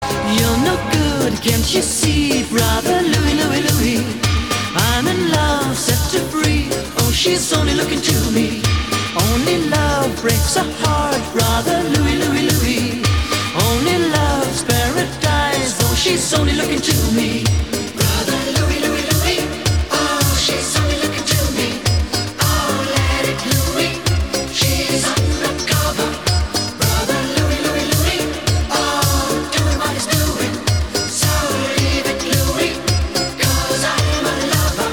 жанр, категория рингтона ЖАНР: РИНГТОНЫ 80е-90е